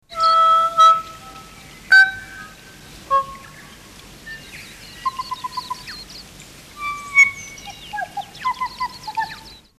zh-pkokako101.mp3